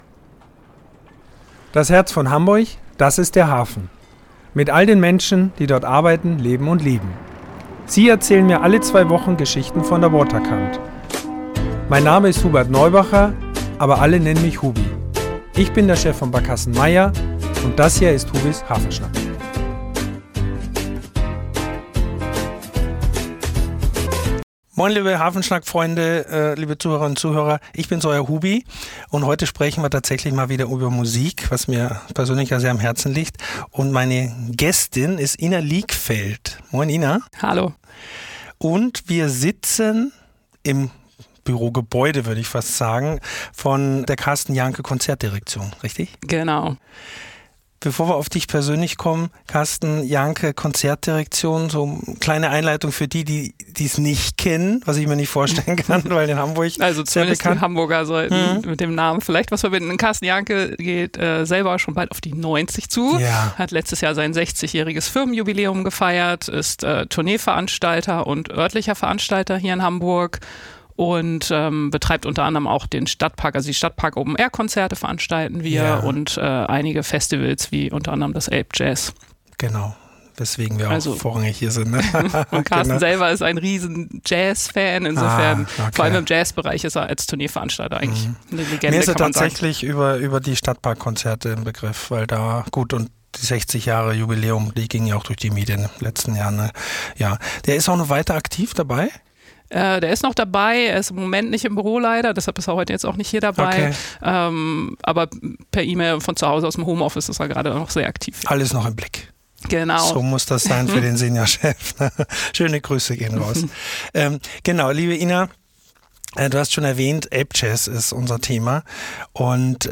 Ein Gespräch über Leidenschaft, Musikentdeckung und die Menschen, die Hamburgs Kultur lebendig machen – direkt aus der Hafenstadt.